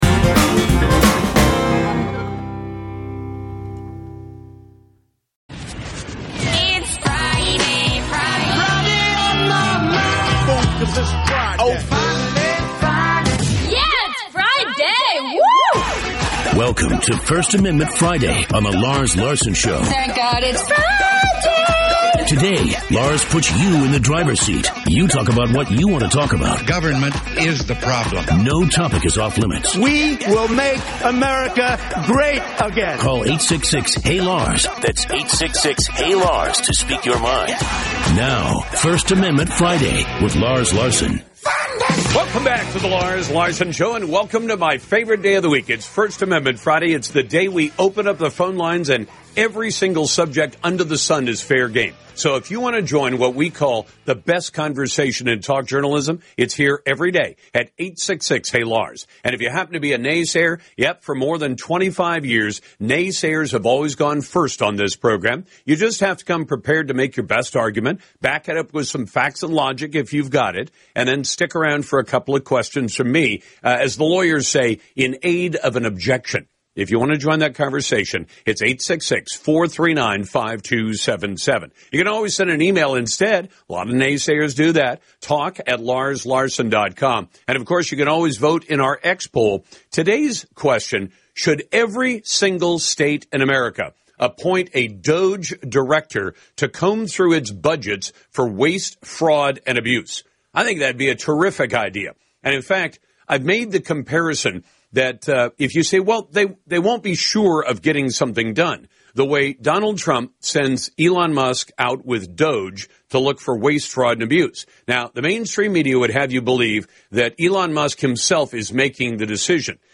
… continue reading 52 епізодів # News # Politics # News Talk # Lars Larson